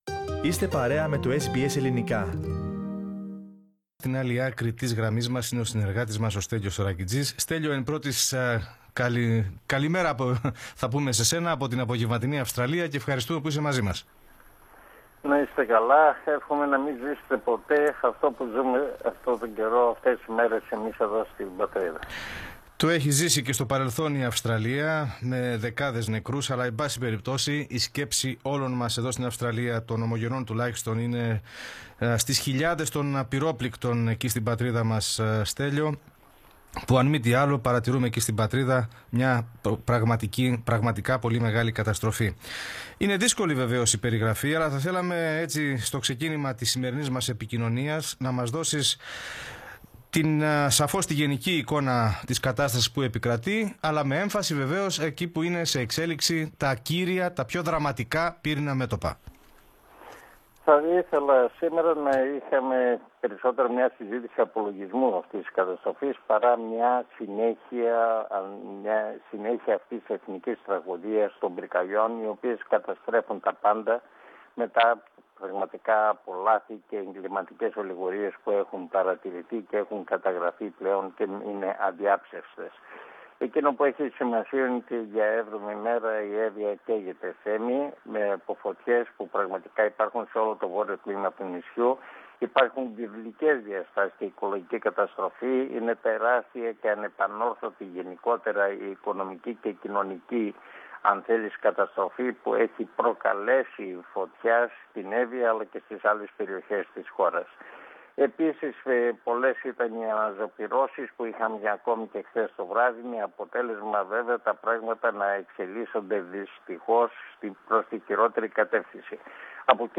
Πατήστε PLAY πάνω στην εικόνα για να ακούσετε την ανταπόκριση του SBS Greek/SBS Ελληνικά.